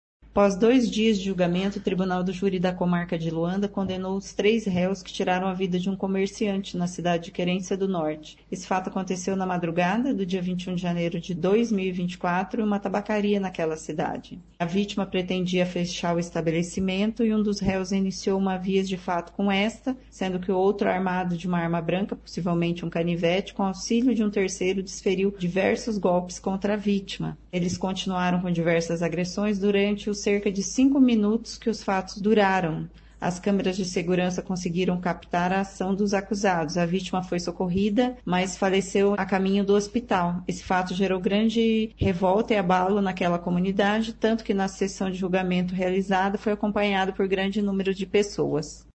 Ouça o que diz a promotora de Justiça Vera de Freitas Mendonça: